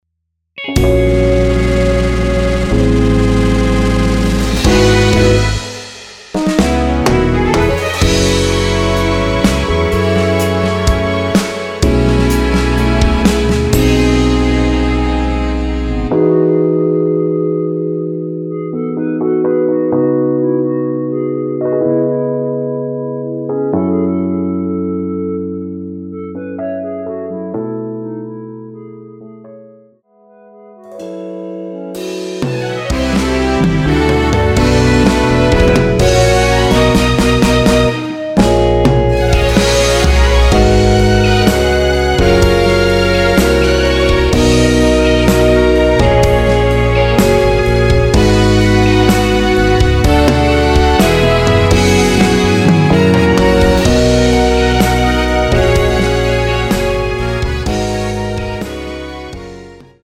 남성분이 부르실수 있는 키로 제작 하였습니다.(미리듣기 참조)
멜로디 MR이라고 합니다.
앞부분30초, 뒷부분30초씩 편집해서 올려 드리고 있습니다.
중간에 음이 끈어지고 다시 나오는 이유는